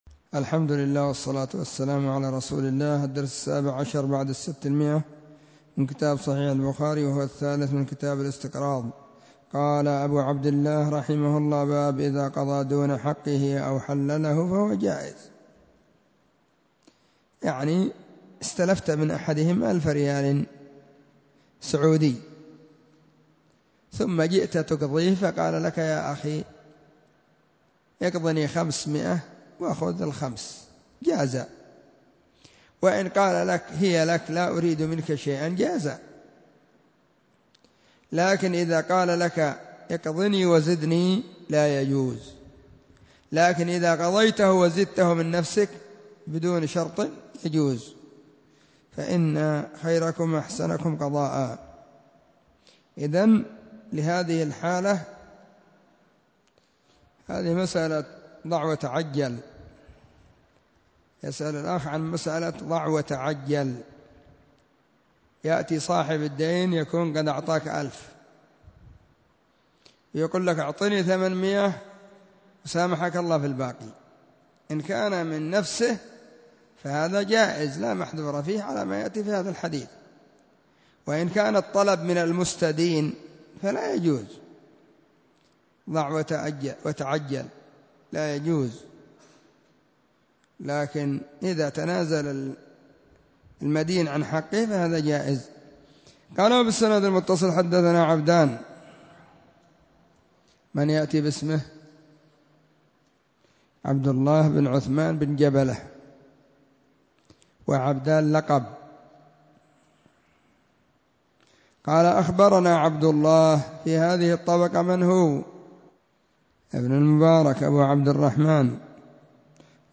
🕐 [بين مغرب وعشاء – الدرس الثاني]
كتاب-الإستقراض-الدرس-3.mp3